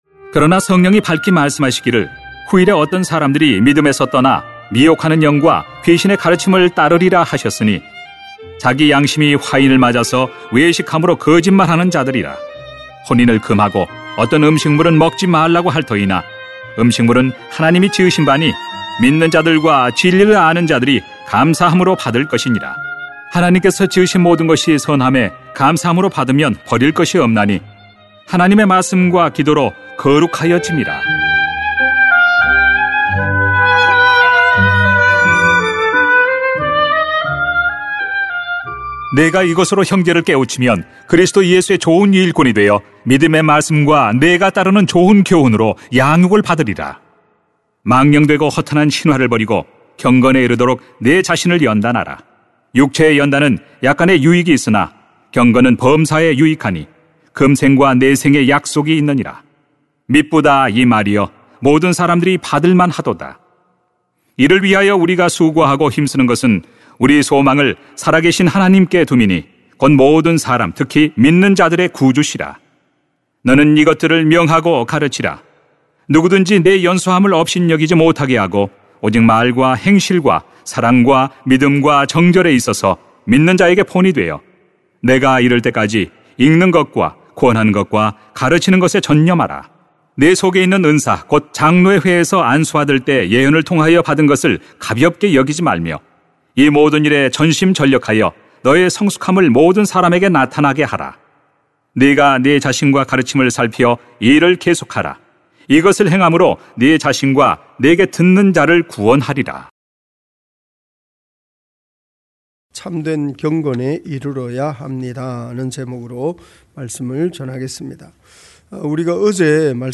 [딤전 4:1-16] 참된 경건에 이르러야 합니다 > 새벽기도회 | 전주제자교회